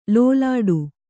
♪ lōlāḍu